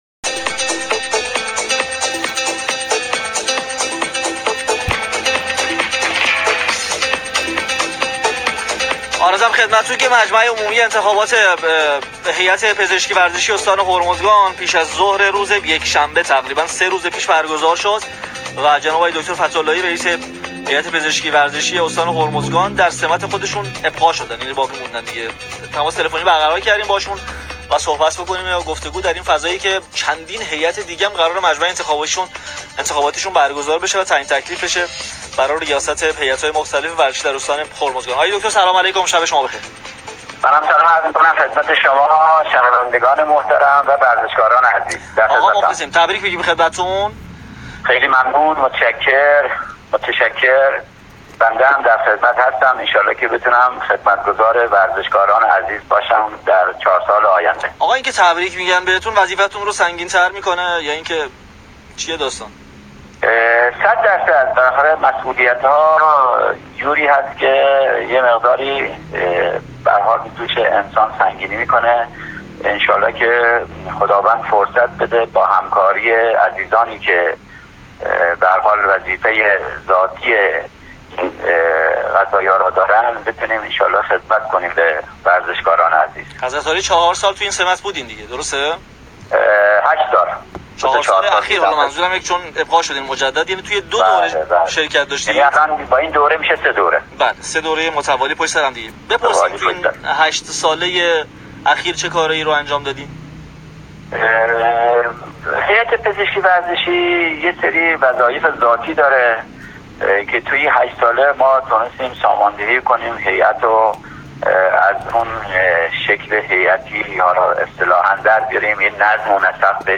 مصاحبه رادیویی